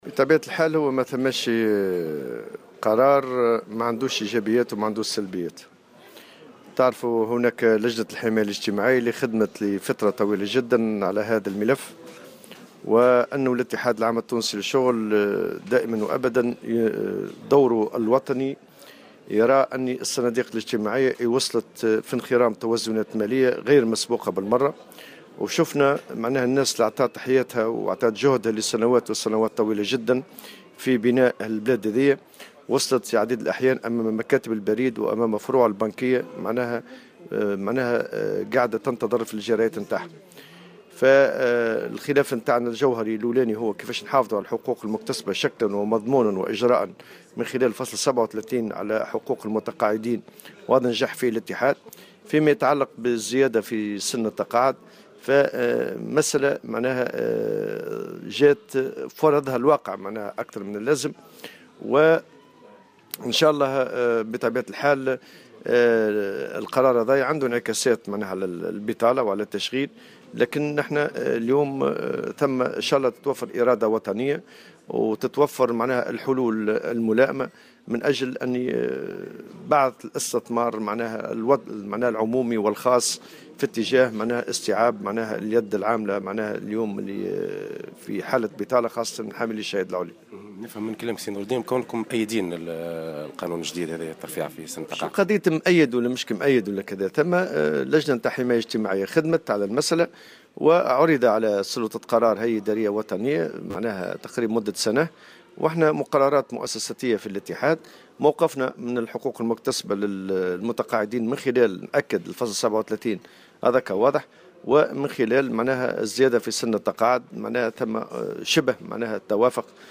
وأضاف في تصريح اليوم لـ"الجوهرة أف أم" على هامش زيارته لولاية سوسة، أن هذا القرار سيكون له إيجابيات وسلبيات أيضا، حيث أنه سيساهم في الحد من الأزمة التي تمر بها الصناديق الإجتماعية، لكن سيكون له انعكاسات سلبية على البطالة والتشغيل، مؤكدا ضرورة العمل على إيجاد حلول ملائمة لدفع الاستثمار العمومي والخاص واستيعاب اليد العاملة خاصة بالنسبة لحاملي الشهادات العليا.